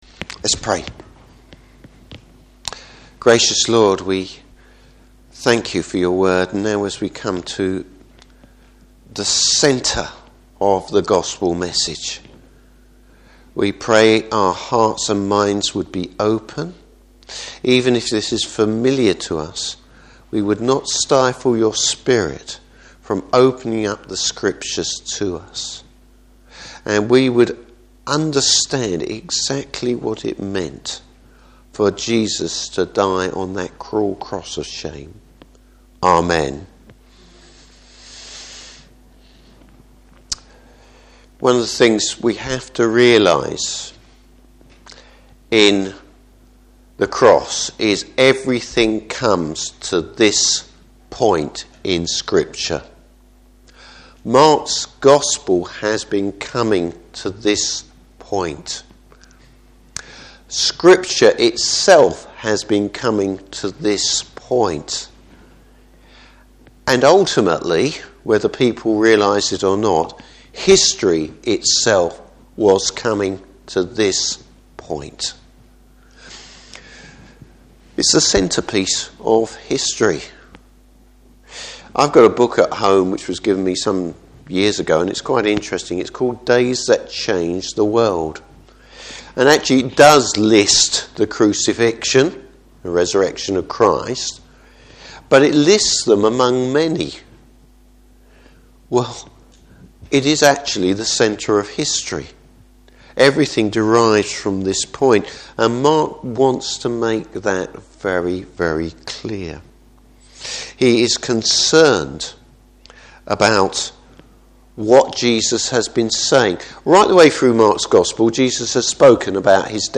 Service Type: Morning Service What’s happening around the Cross?